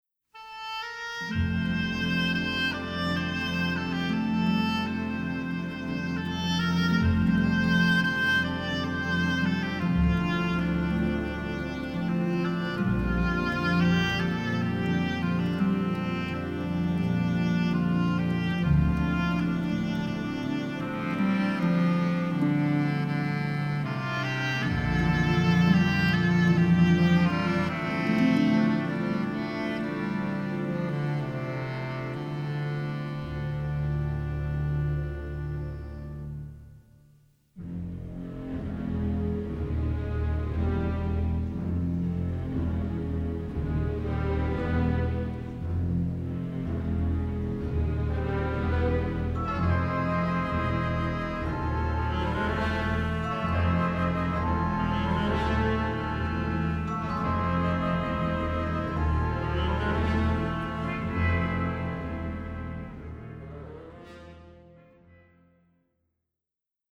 unabashedly romantic
suspenseful and brooding, pastoral and uplifting